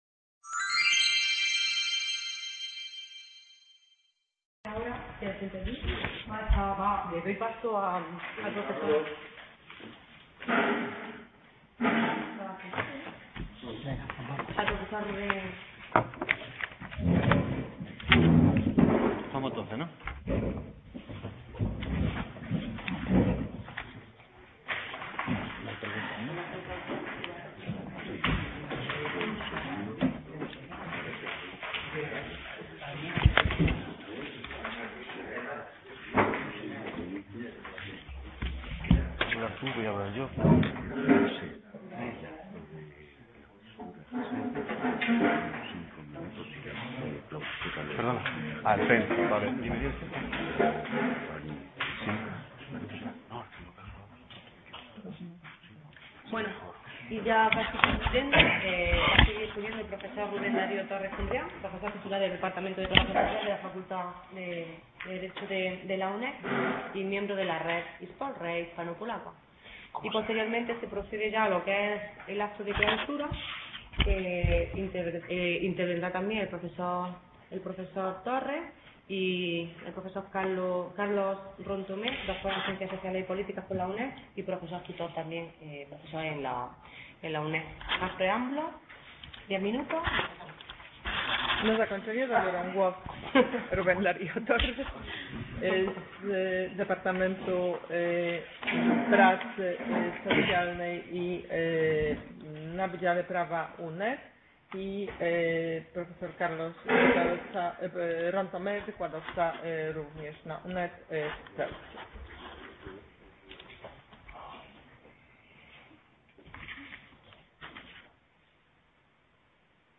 Inmigración, Refugiados: Ideologías, Discursos y Manifestaciones Sociales del Odio CA Ceuta - Seminario Permanente Internacional de Seguridad Interior y Exterior de la Unión Europea